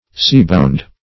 Seabound \Sea"bound`\, a. Bounded by the sea.